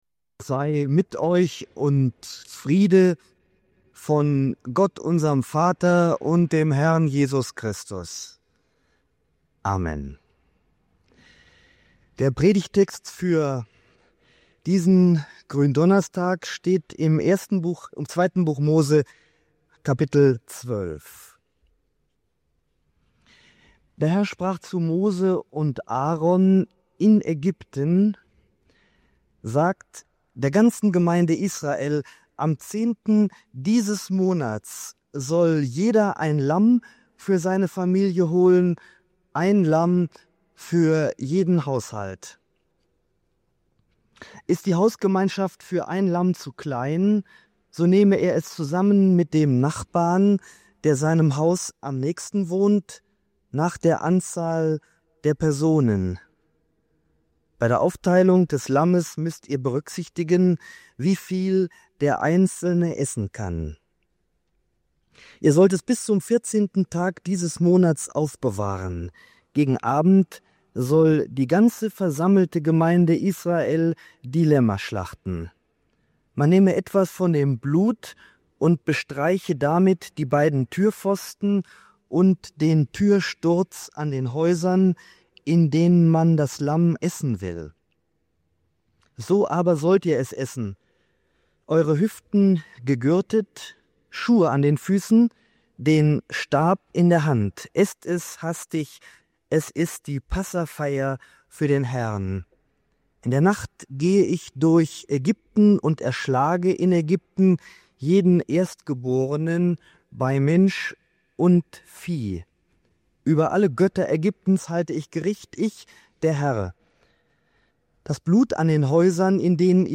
Die Predigt entfaltet eindrücklich, wie eng Passah und Abendmahl miteinander verbunden sind – als Feste der Erinnerung, der Erlösung und des Aufbruchs. Dabei wird deutlich, dass „Gedächtnis“ im biblischen Sinn weit mehr ist als ein bloßes Zurückdenken.